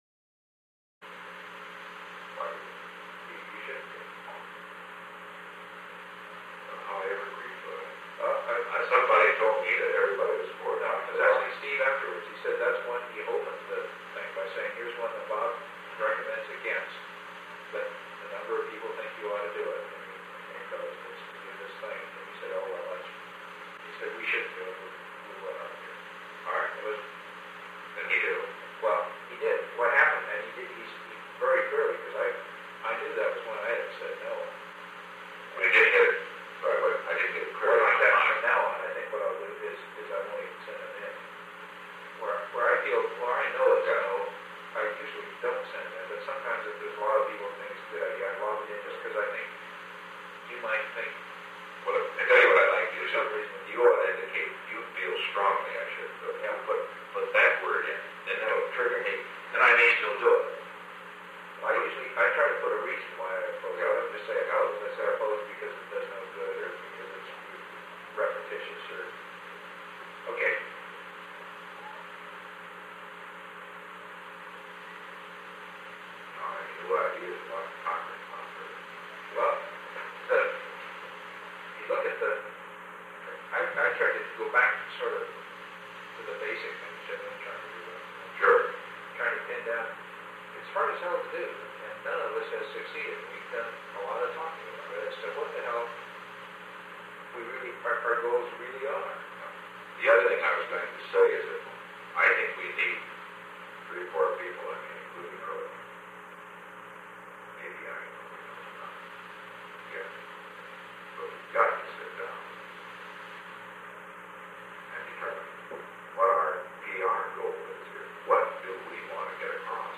Conversation No. 418-2 Date: March 7, 1973 Time: Unknown between 4:15 pm and 5:50 pm Location: Executive Office Building The President met with H. R. (“Bob”) Haldeman.
Secret White House Tapes